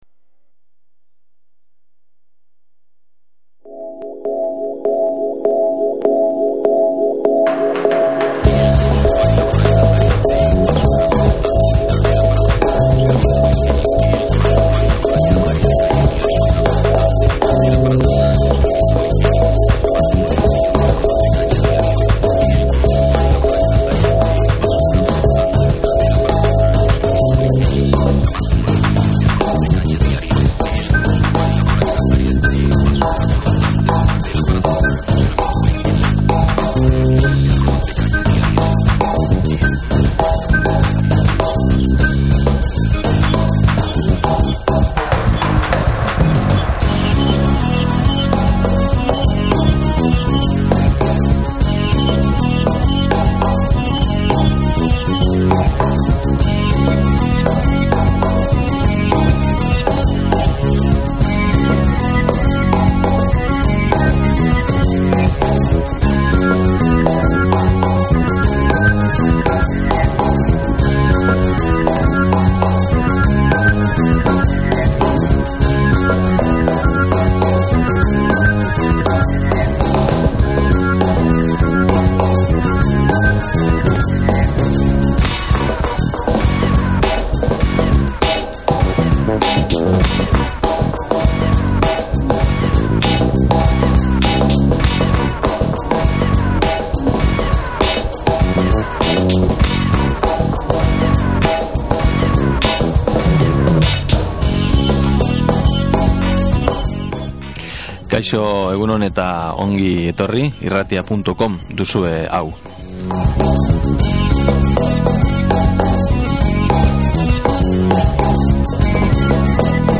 Skype bidezko elkarrizketan hango bizitzaz, mugikorren iraultzaz, blogak, flickr, youtube… izan ditugu hizpide. Gero, gure musikarien nazioarteko ibilbideetaz mintzatu gara.